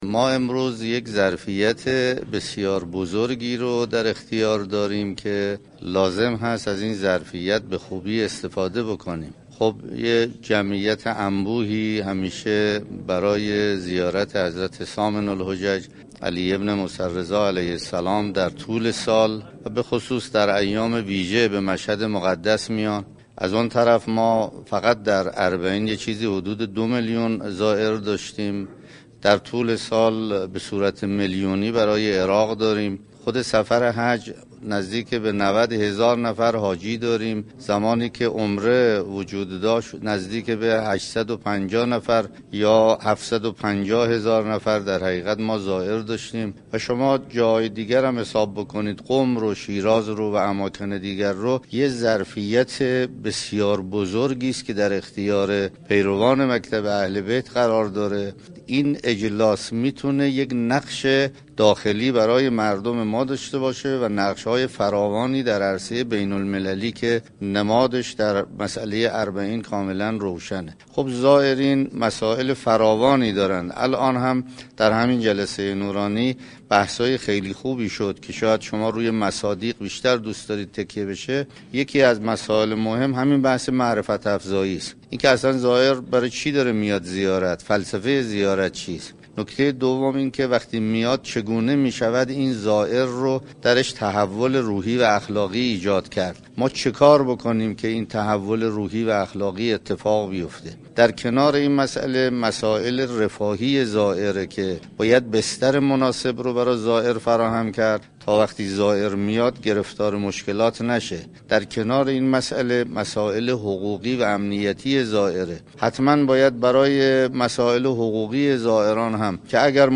حجت الاسلام والمسلمین سید علی قاضی عسكر در نشست تولیت های آستان های مقدس ایران در حرم مطهر رضوی گفت : امروز ظرفیت بزرگی را در اختیار داریم، جمعیت زیادی برای زیارت به مشهد می آیند و این ظرفیت عظیمی كه باید از آن بهره برد.